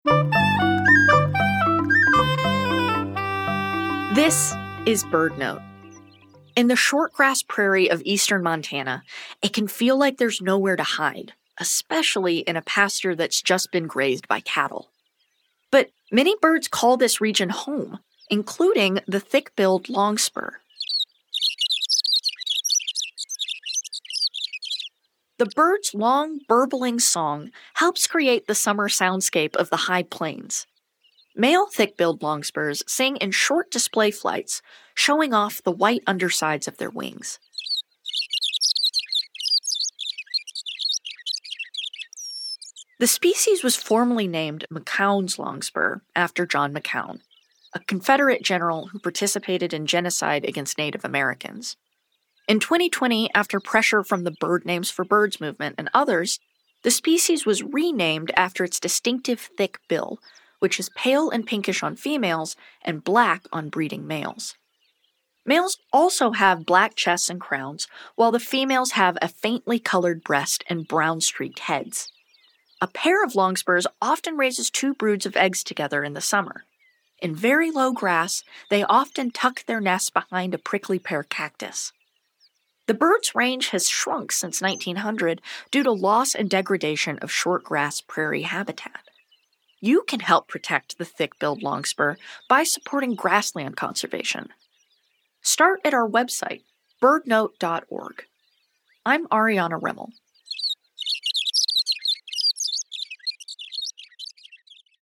The bird’s burbling song helps create the high plains’ soundscape.